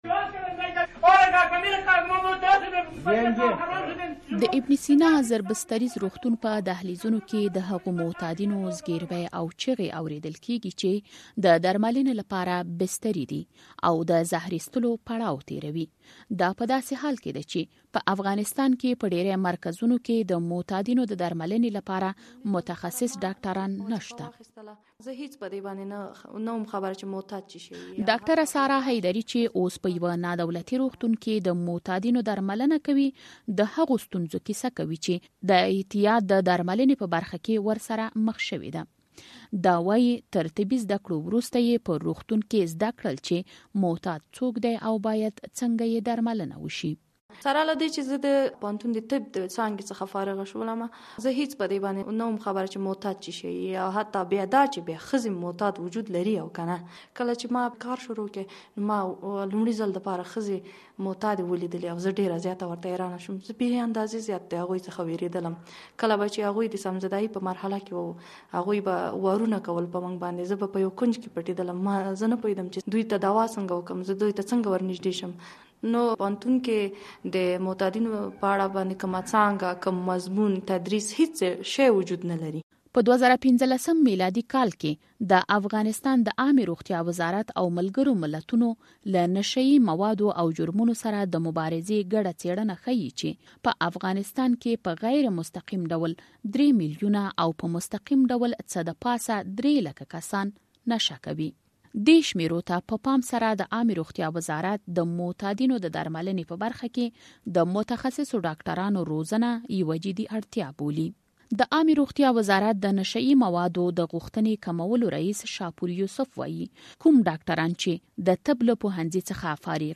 د روغتون فضا...
د ابن سینا زر بستریز روغتون په دهلیزونو کې د هغو معتادینو زګېروی او چیغې اورېدل کېږي چې د درملنې لپاره بستري دي او د زهر ایستلو پړاو تېروي.